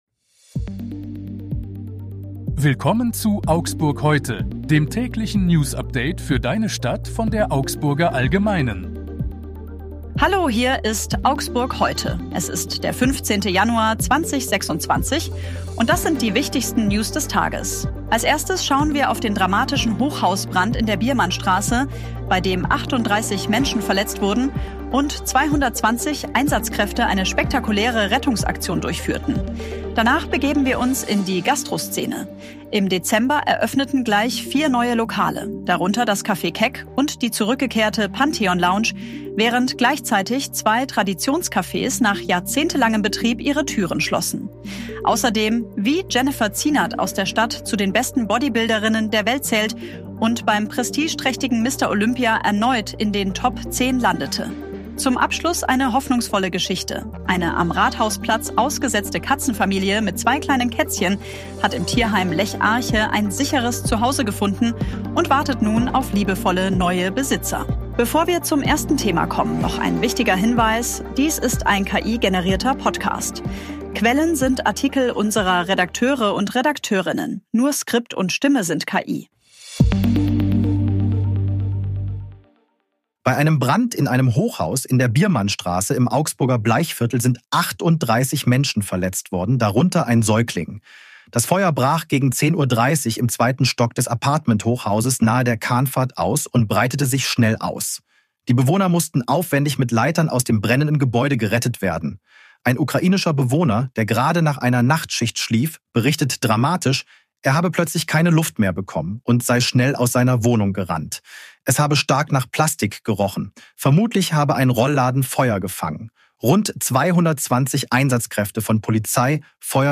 Die aktuellen Nachrichten aus Augsburg vom 15. Januar 2026.
Nur Skript und Stimme sind KI.